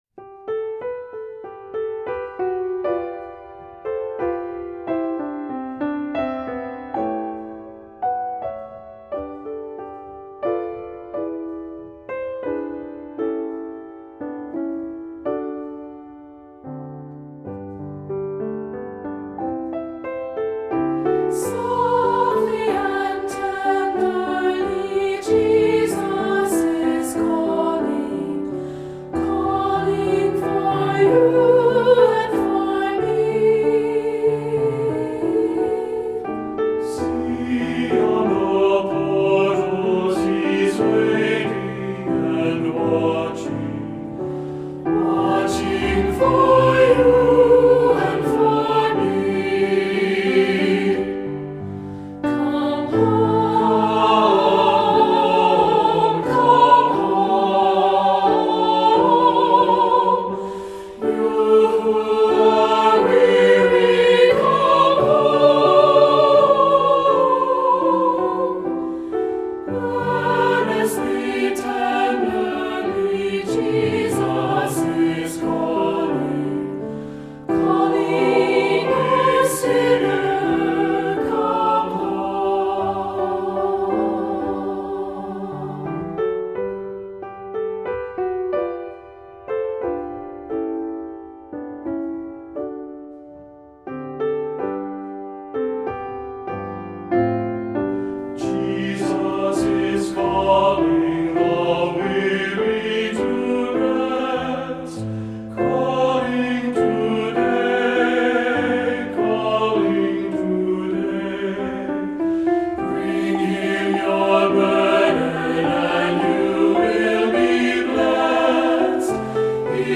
Voicing: 2-Part Mixed